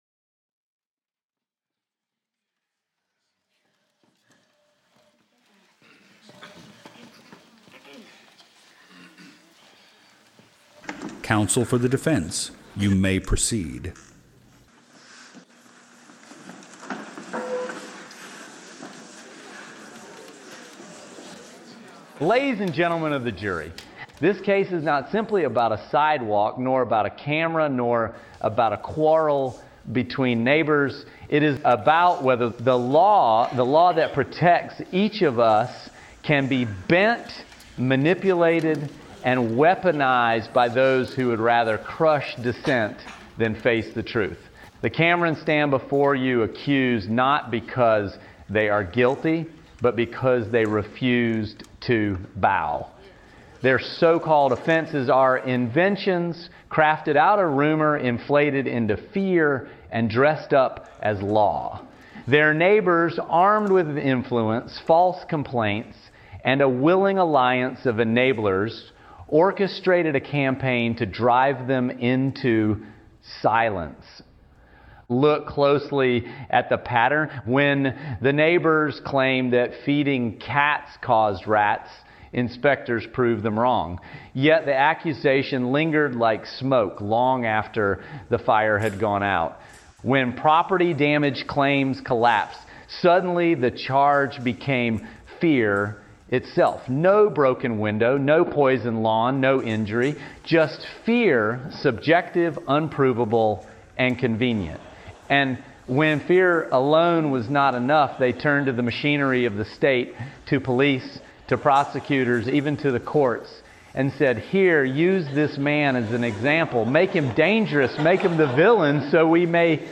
Listening Room • Courtroom Feature